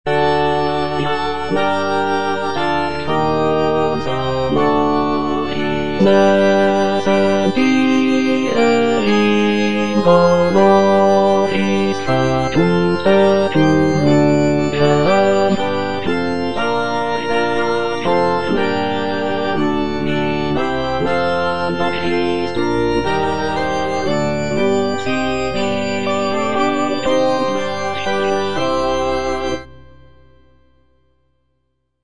G.P. DA PALESTRINA - STABAT MATER Eja Mater, fons amoris (bass I) (Emphasised voice and other voices) Ads stop: auto-stop
a sacred choral work